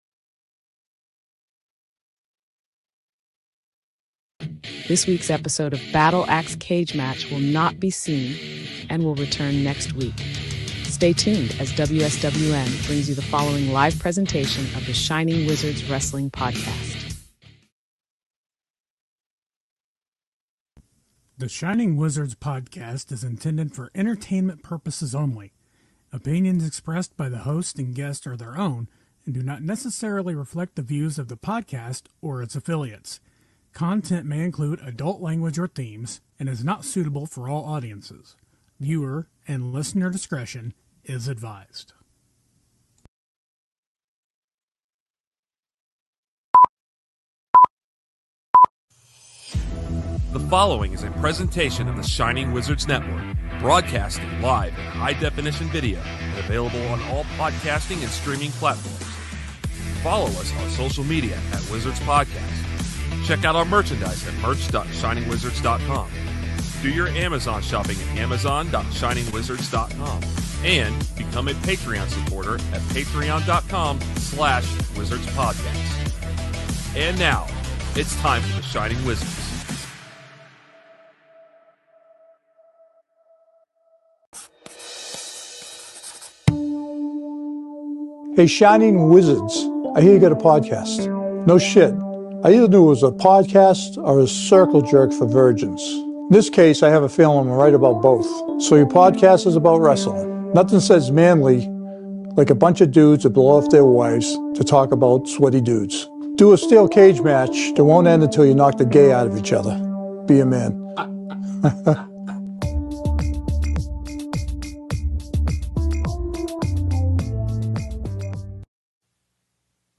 The Wizards return, but with a 3 man crew